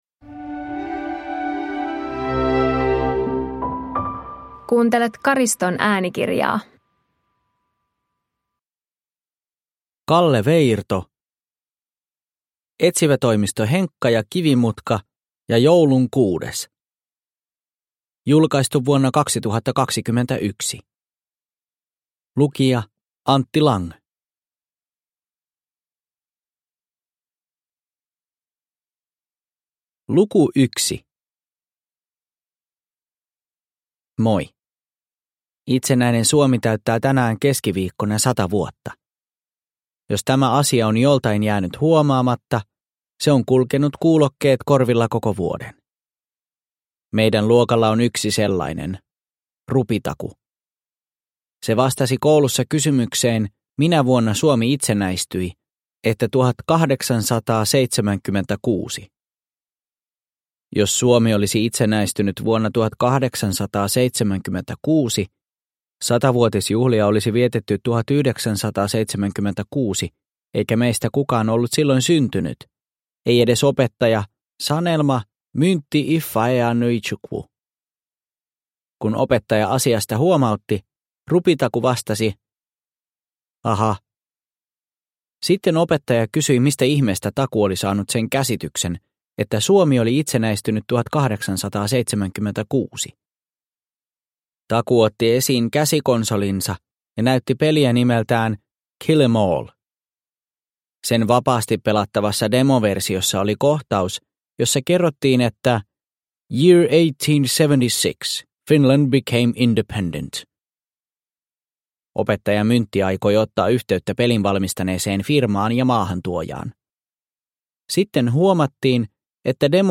Etsivätoimisto Henkka & Kivimutka ja joulukuun kuudes – Ljudbok